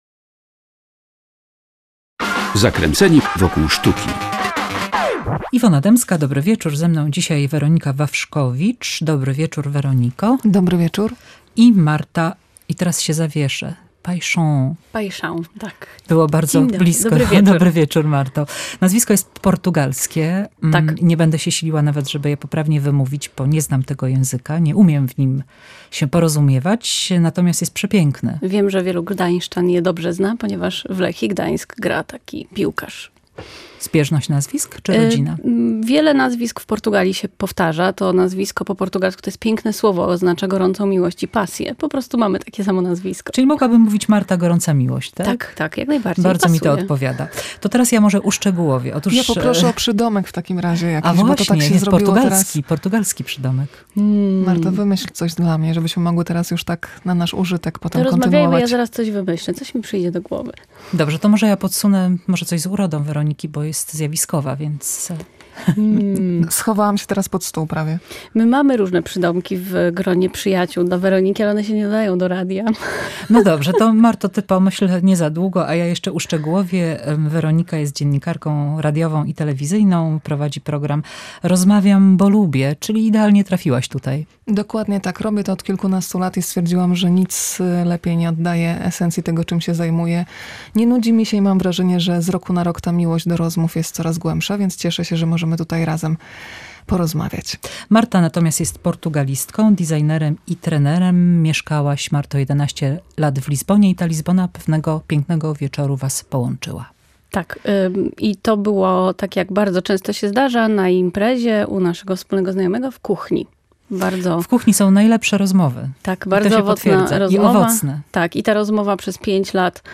Połączyła je Lizbona – miasto, które przytula. Rozmowa